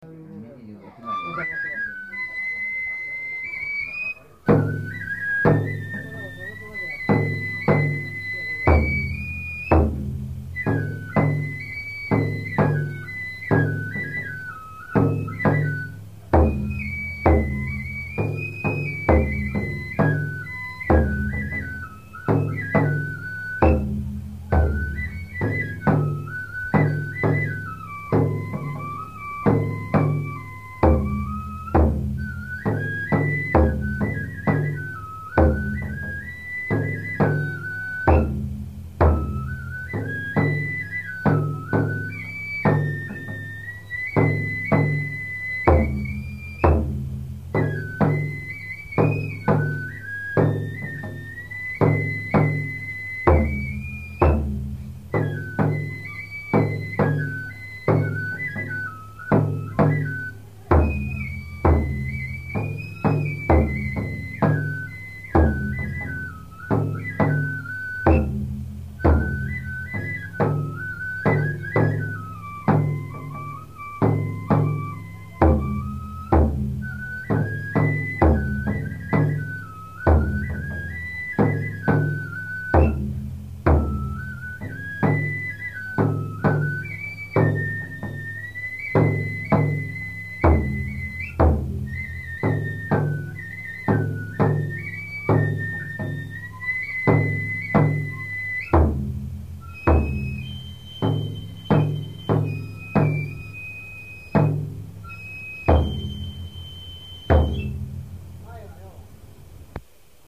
津賀田神社神楽の公開録音会　（昭和３３年１０月２８日）